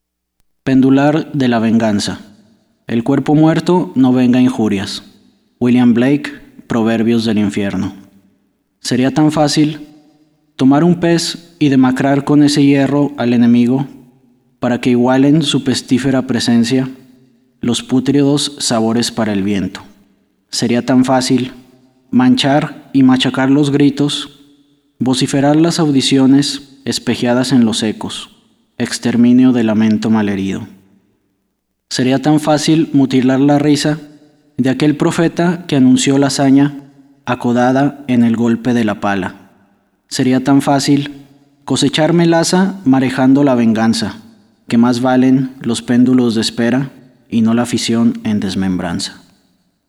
Haga clic para escuchar al autor